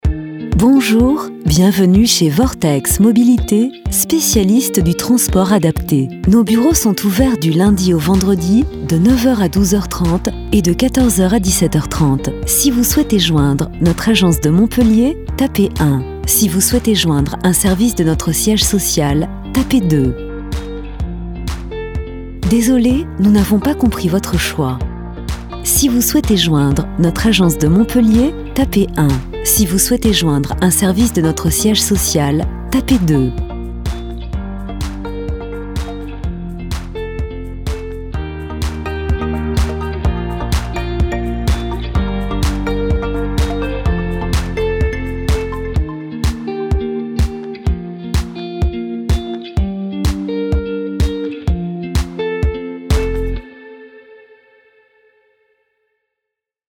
Commerciale, Naturelle, Enjouée, Polyvalente, Corporative